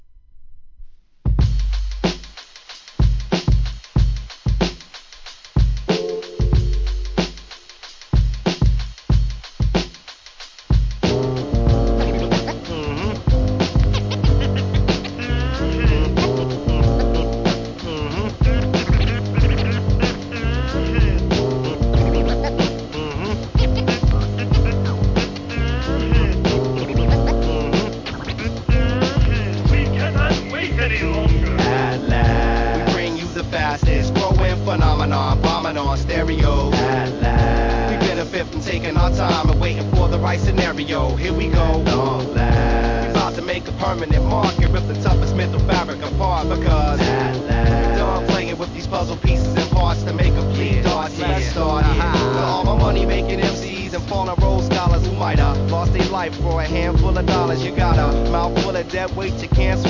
HIP HOP/R&B
ジャジー・アトランタ・アンダーグランド！